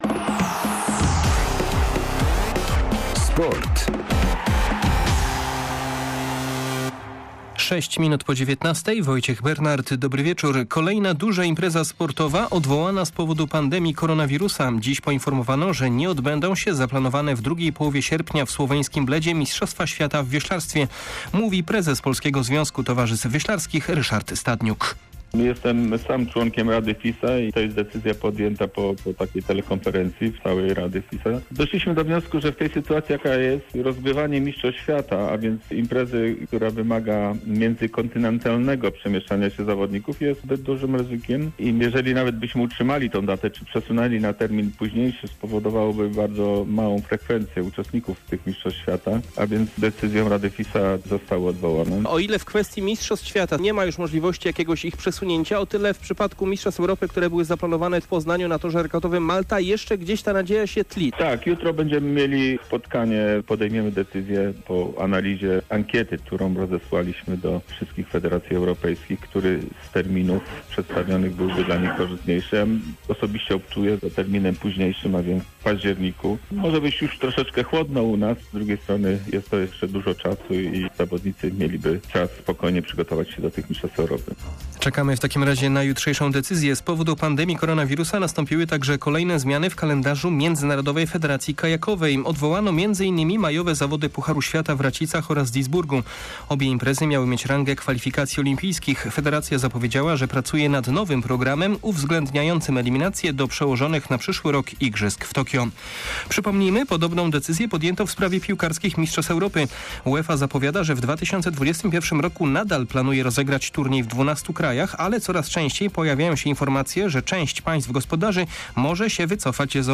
09.04. SERWIS SPORTOWY GODZ. 19:05